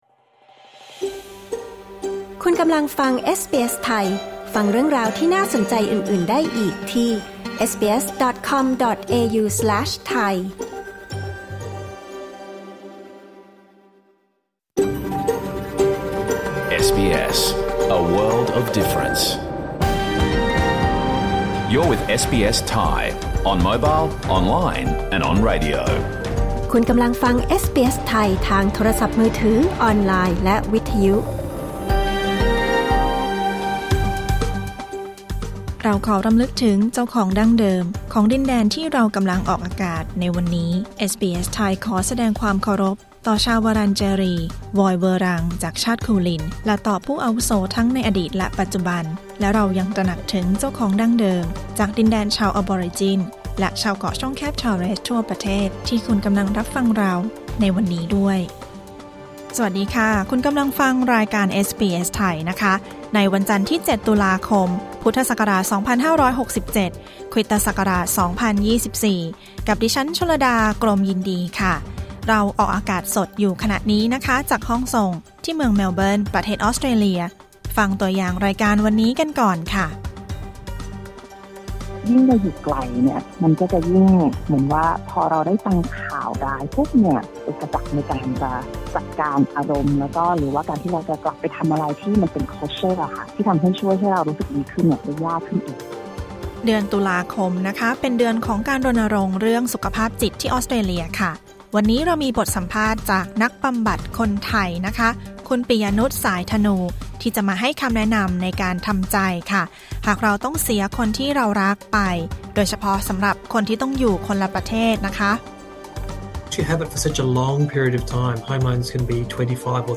รายการสด 7 ตุลาคม 2567